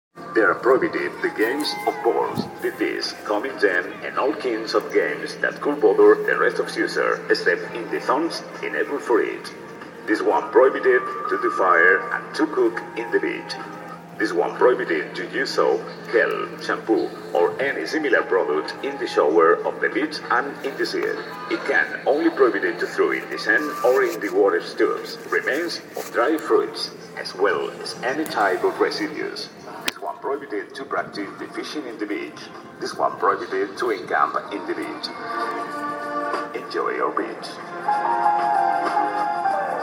From the loudspeaker at Barbate beach, Cadiz province